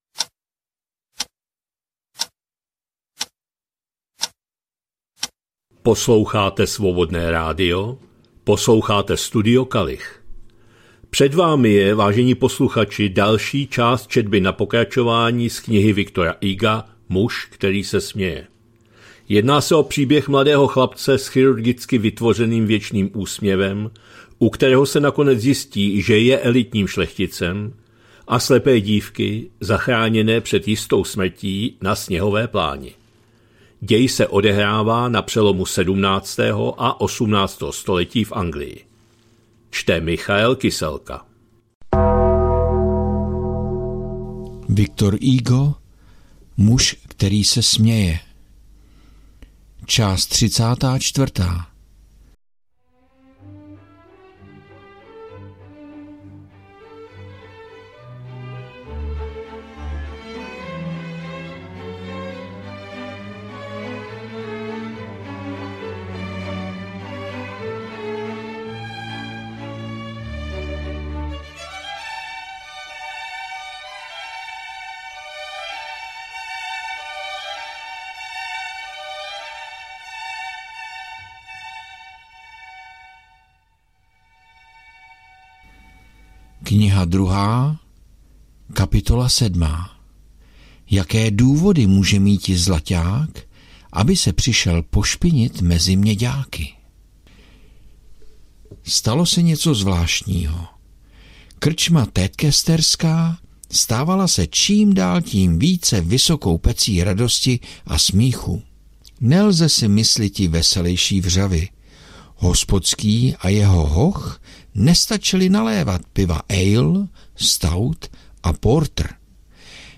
2025-11-07 - Studio Kalich - Muž který se směje, V. Hugo, část 34., četba na pokračování